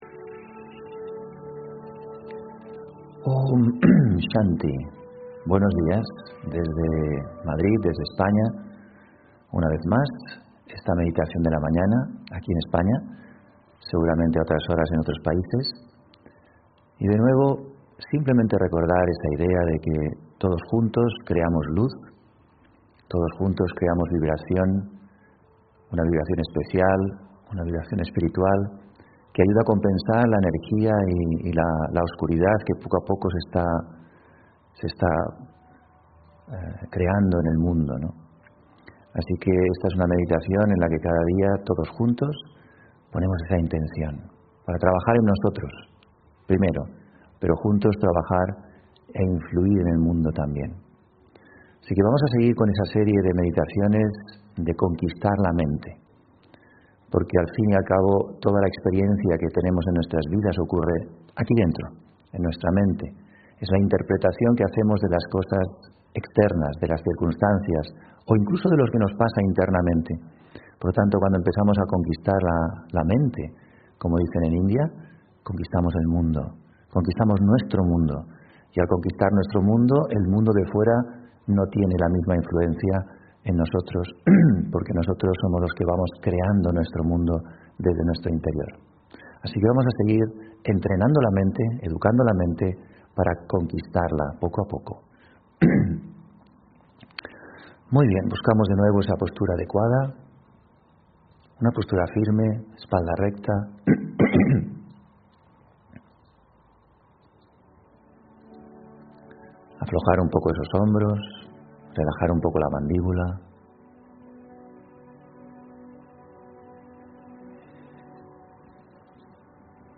Meditación y conferencia: La felicidad de servir (24 Febrero 2023)